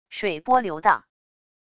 水波流荡wav音频